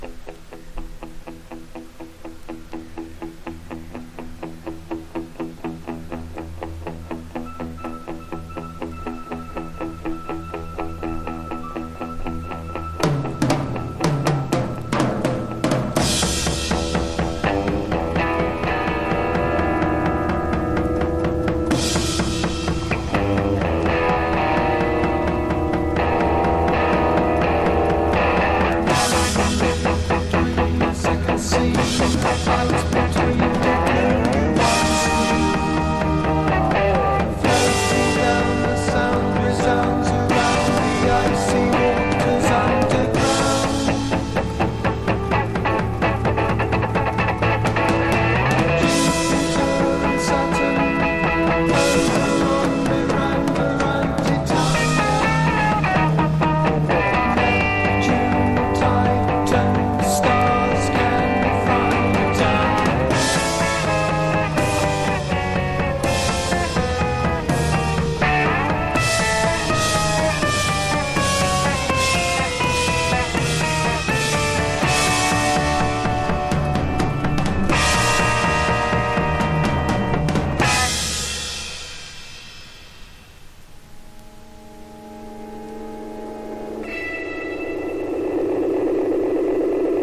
1. 70'S ROCK >